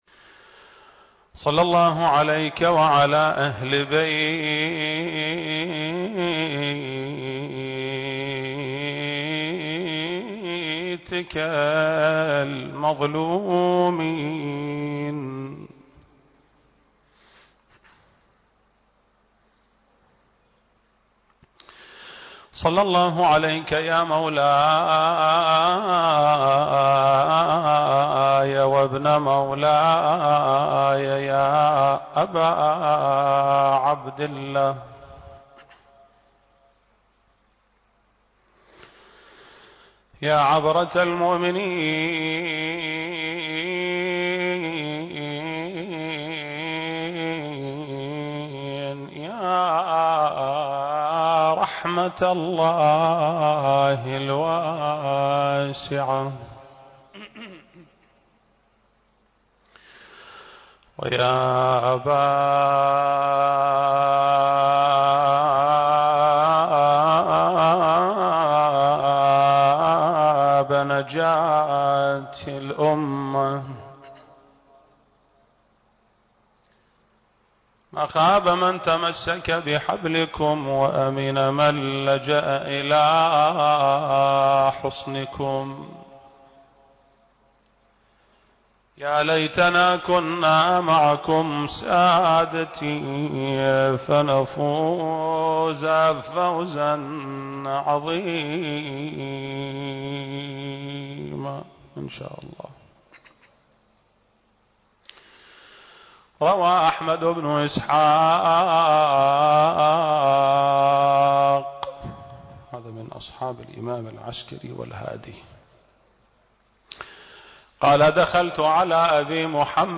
المكان: مسجد آل محمد (صلّى الله عليه وآله وسلم) - البصرة التاريخ: شهر رمضان المبارك - 1442 للهجرة